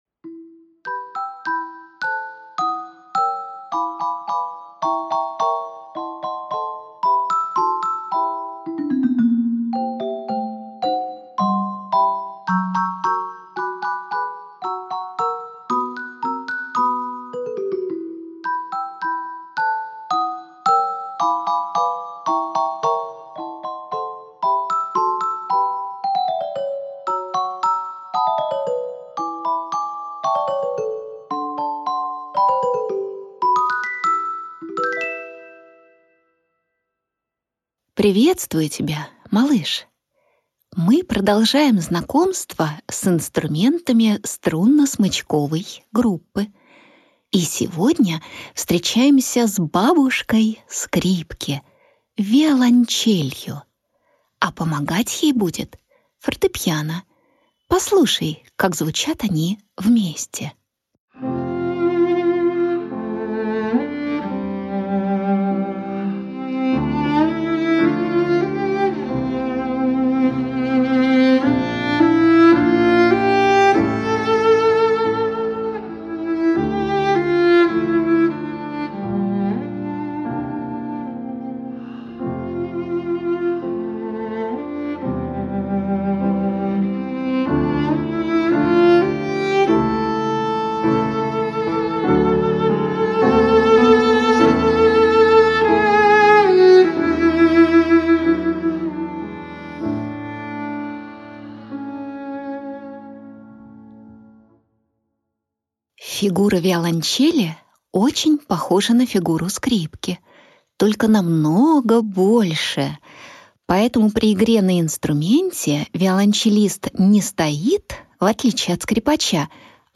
Аудиокнига Волшебница виолончель. Сказка в сказке | Библиотека аудиокниг